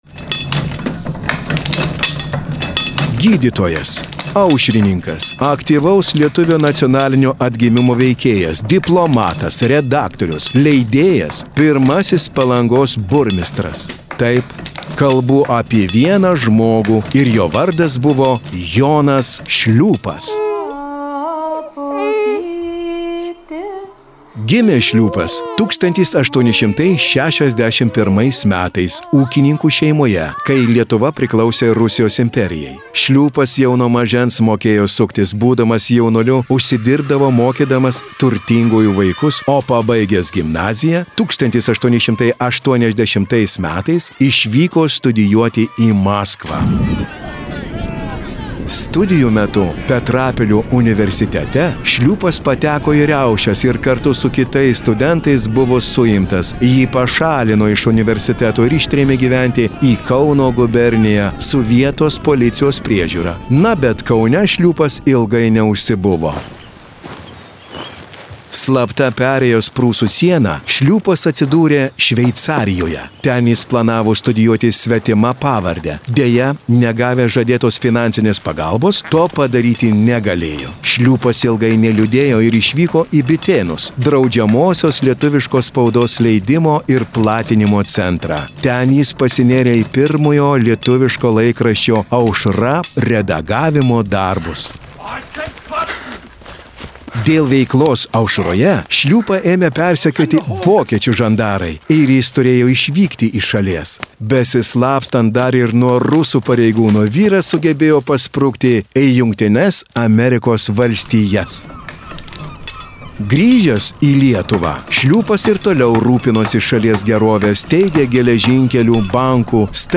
Projekto „Kurortų paveldas išmaniai“ Palangos objektų audiogidai: